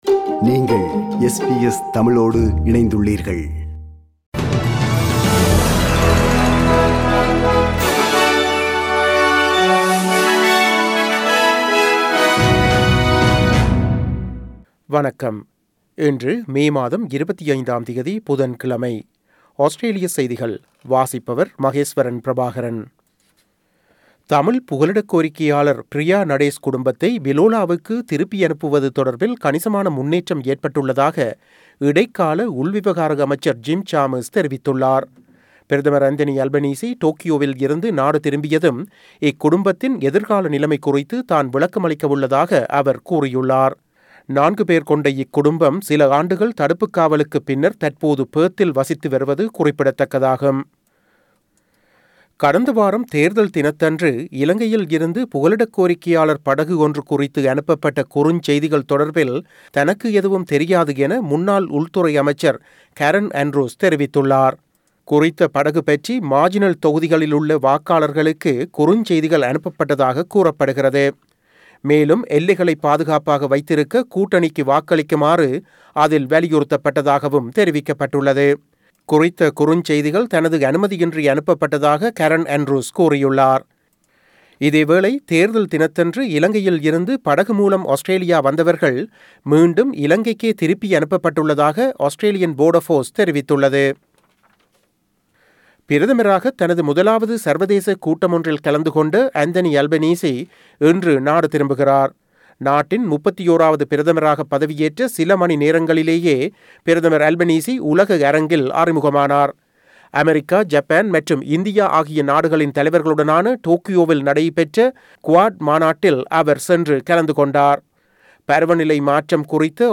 Australian news bulletin for Wednesday 25 May 2022.